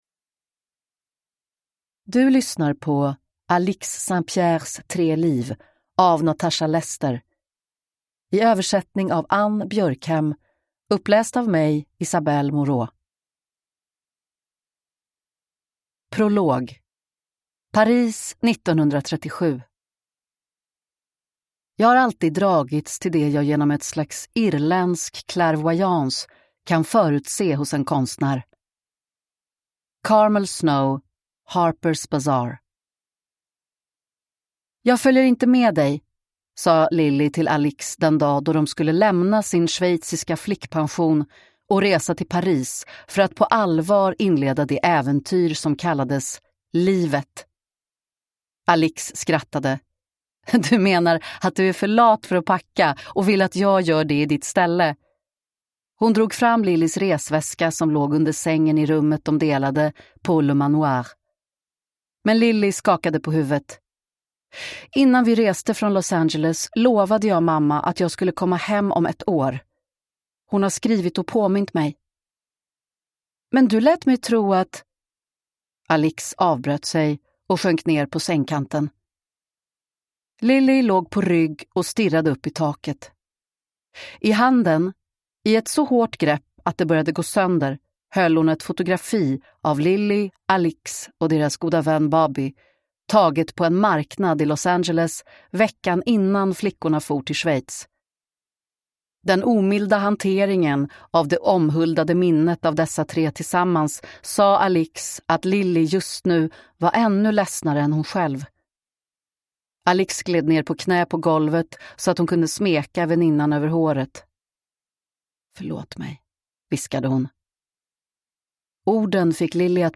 Alix St. Pierres tre liv – Ljudbok